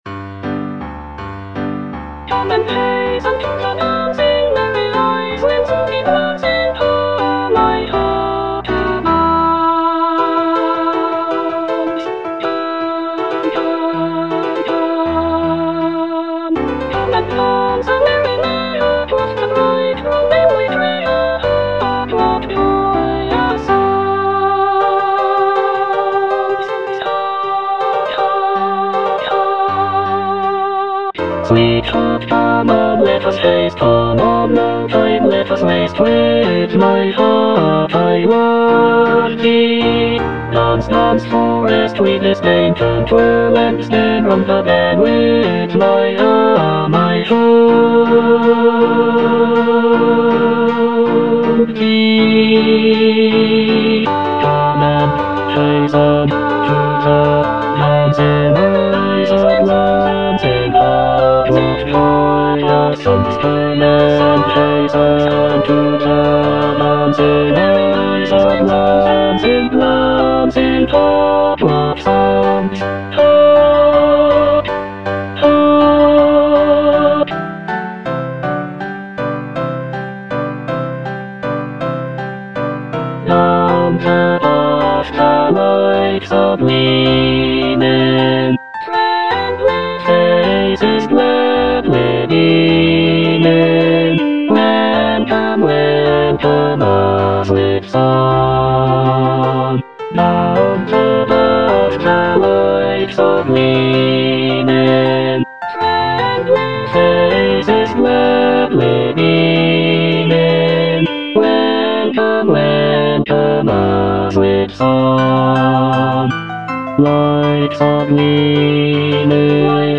E. ELGAR - FROM THE BAVARIAN HIGHLANDS The dance (bass II) (Emphasised voice and other voices) Ads stop: auto-stop